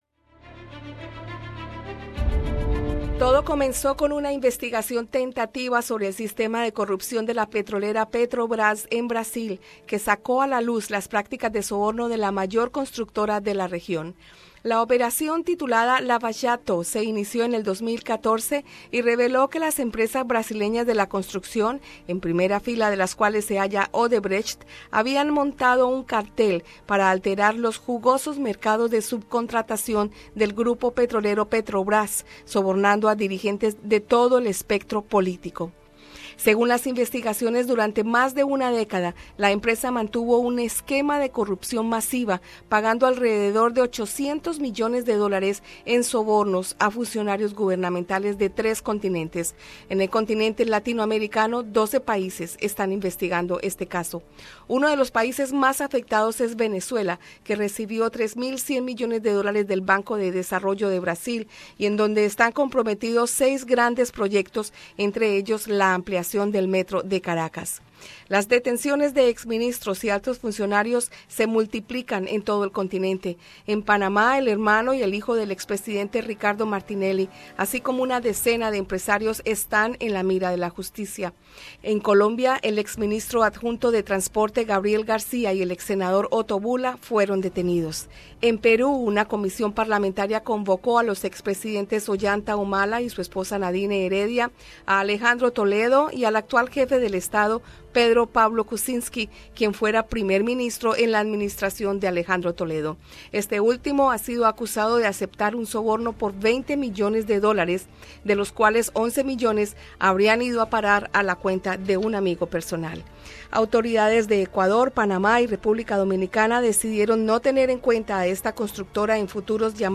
Entrevista con el historiador